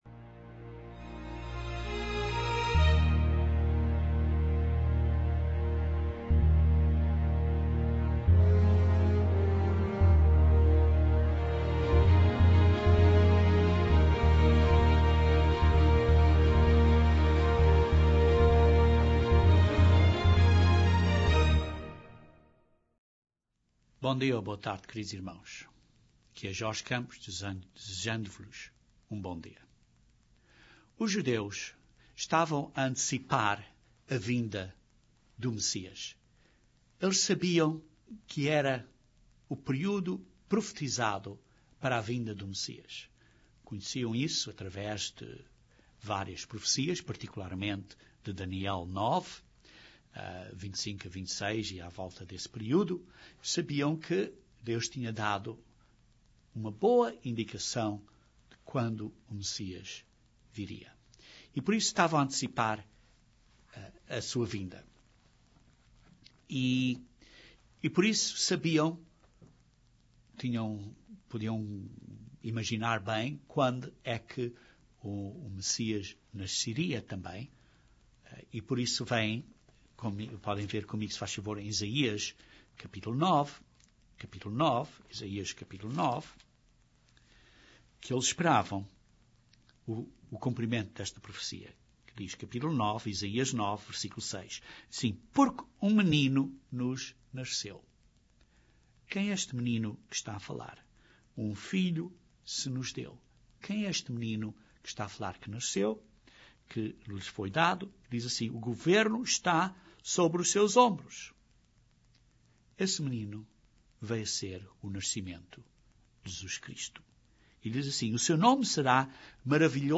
Este sermão estuda algumas das profecias da segunda vinda do Messias, para estabelecer o Reino de Deus na terra, inicialmente por 1000 anos.